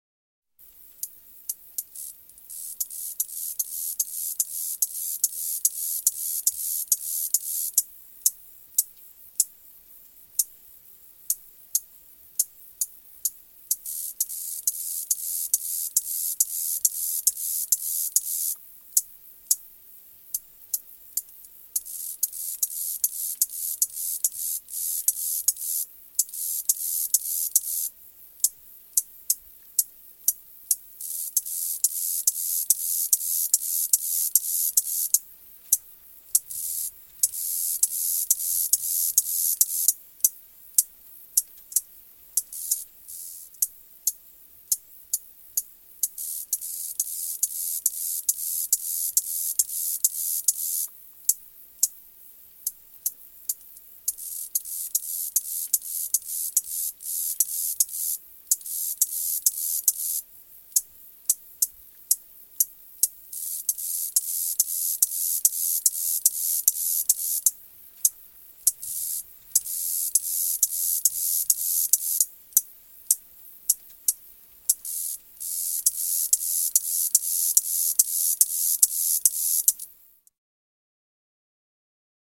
Здесь вы найдете стрекотание в разных тональностях, от одиночных особей до хора насекомых.
Звук стрекота кузнечика в густой траве